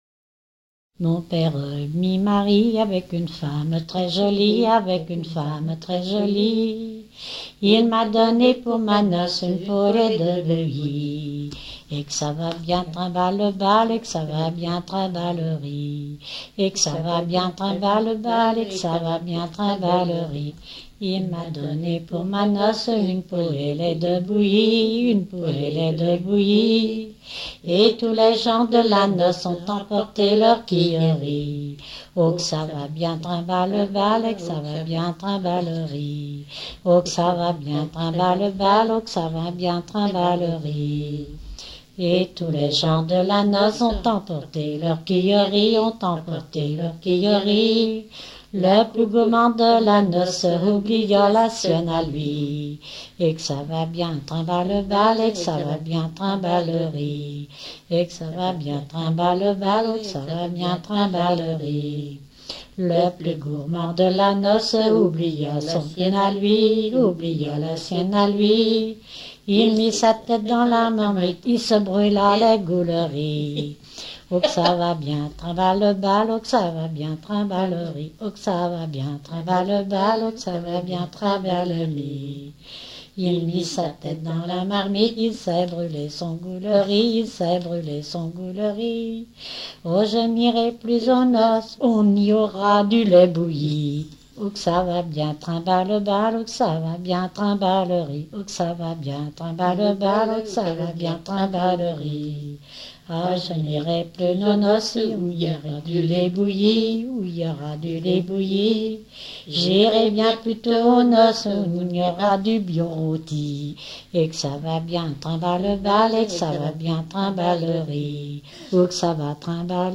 Dompierre-sur-Yon
Genre laisse
Pièce musicale éditée